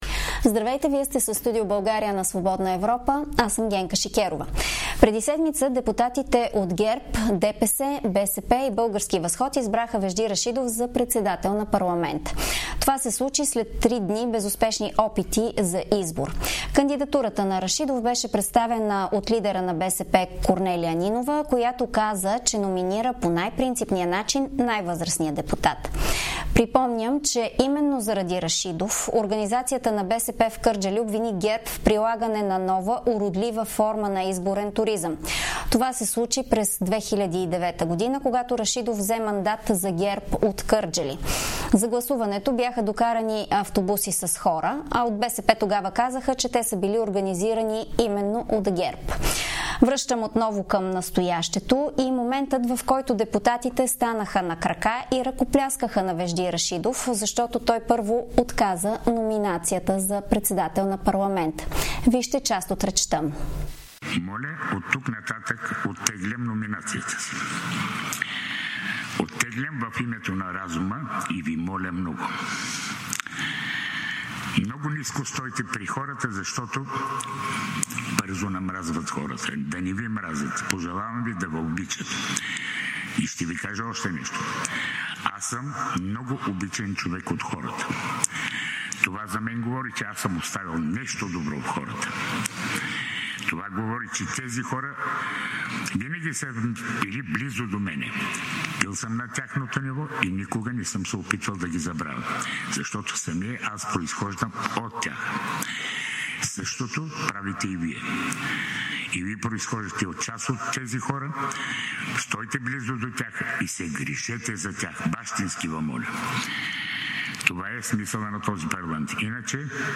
в интервю пред Свободна Европа за избора на Вежди Рашидов за председател на парламента